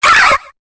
Cri de Carabing dans Pokémon Épée et Bouclier.